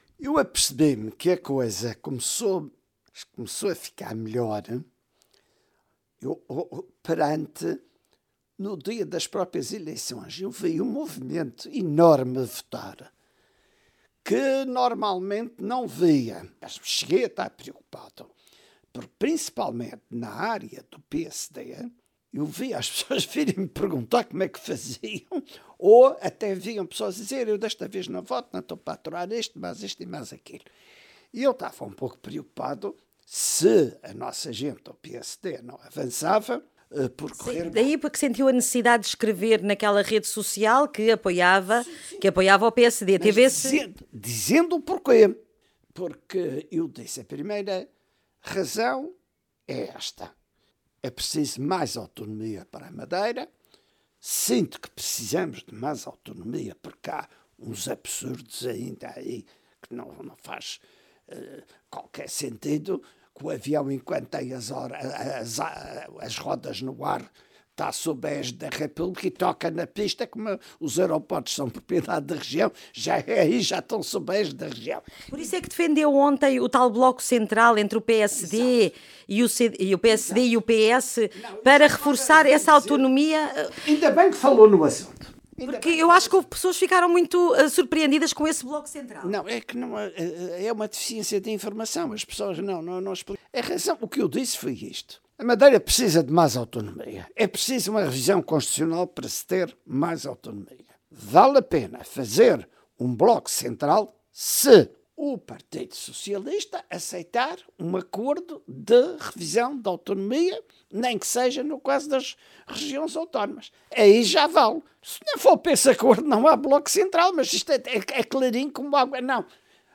Em entrevista à Renascença no dia seguinte às eleições regionais da Madeira, Alberto Joao Jardim diz que Miguel Albuquerque tem de saber aproveitar esta oportunidade única de união no PSD. Jardim fala do PSD como um partido que perdeu o ADN e ligado aos interesses económicos.